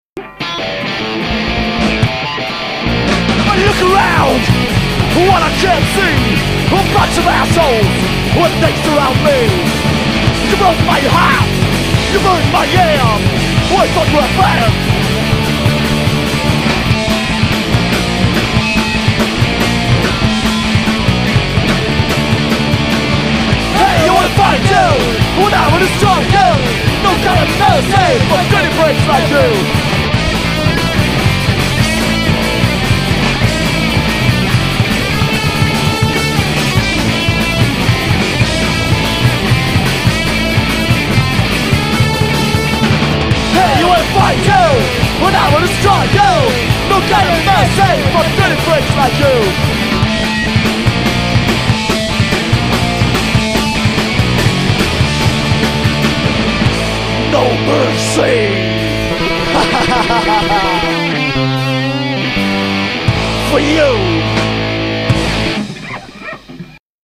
Live in der KVU: Mitschnitte vom Konzert am 11.12.2004